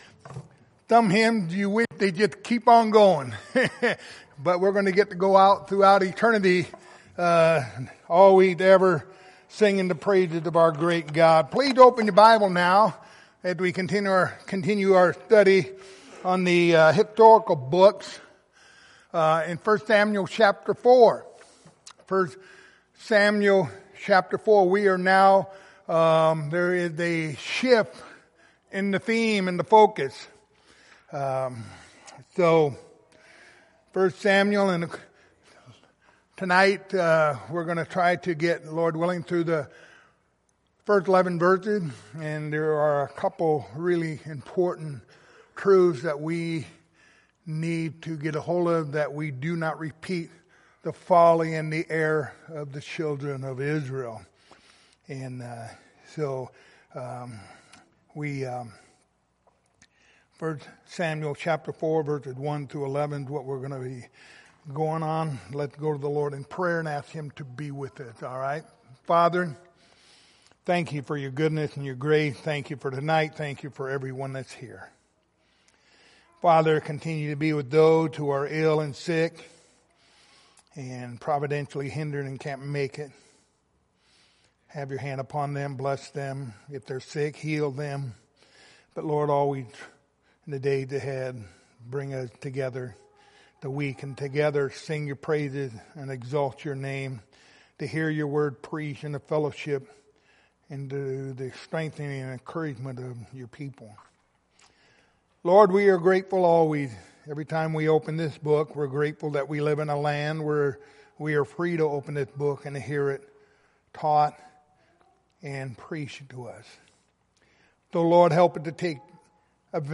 Passage: 1 Samuel 4:1-11 Service Type: Wednesday Evening